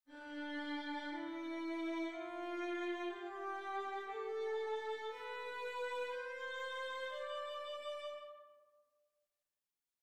Now, modes are different, each has its own pattern: if, instead of making C the centre of things, we move up one note and focus on D, by playing the scale (white notes only!) to the next D up, we arrive at a new pattern (TSTTTST); it’s called the Dorian mode.
Dorian
dorian.mp3